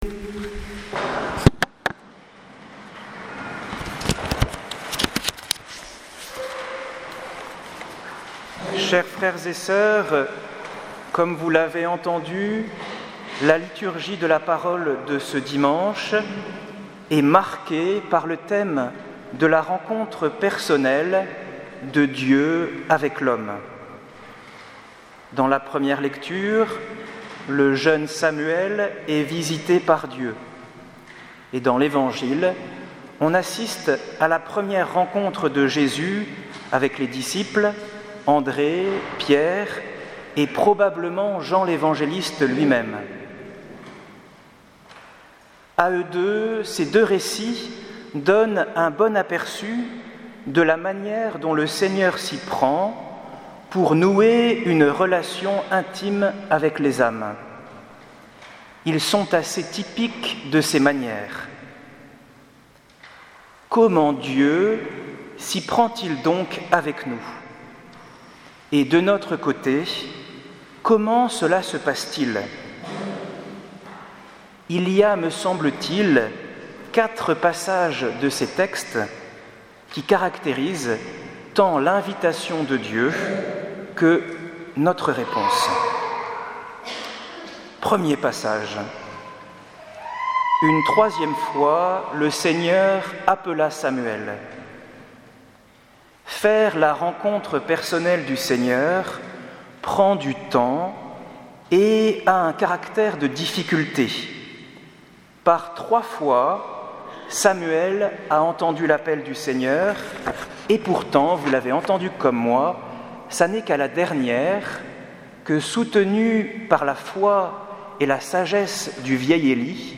Homélie du dimanche 14 janvier 2018